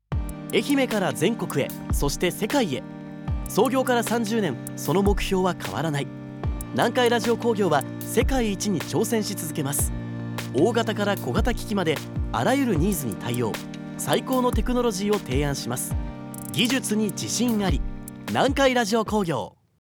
CMサンプル その２（イベント告知篇）
南海放送スタジオにてアナウンサーのナレーション録りと編集